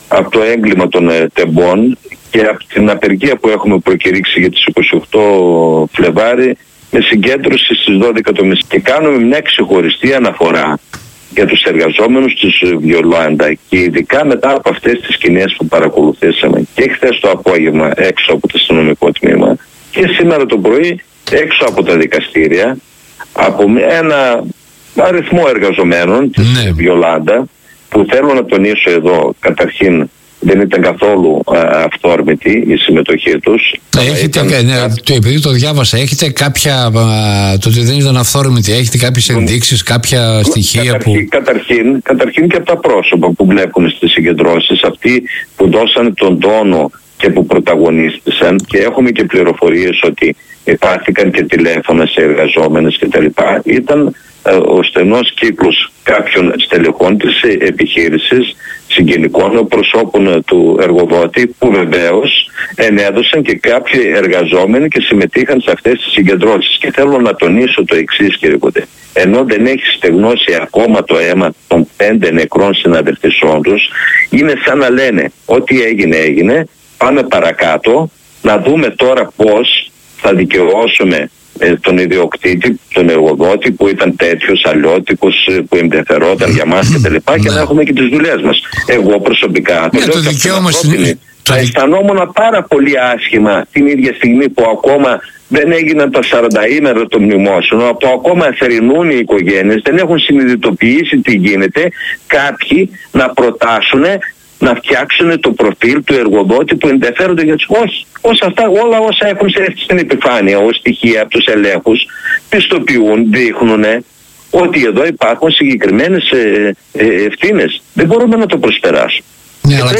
στην εκπομπή Χαμηλές Πτήσεις στον ΖΥΓΟ